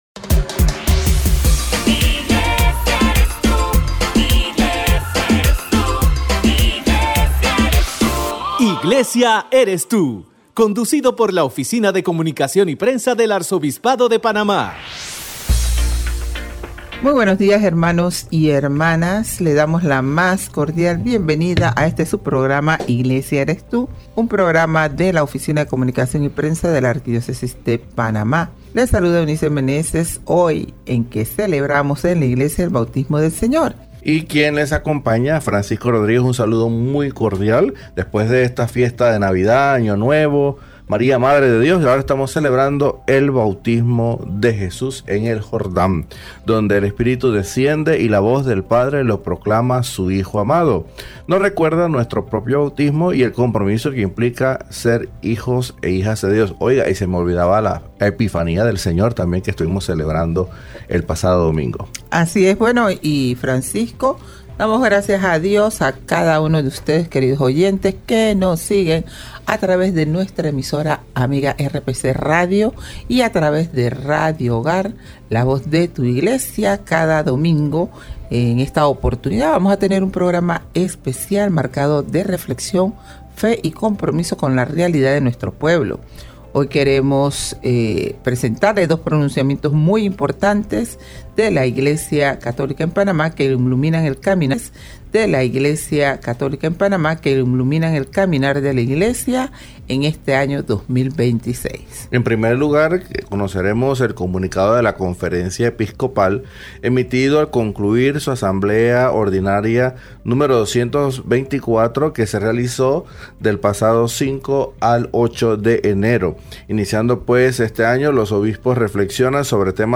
PROGRAMA RADIAL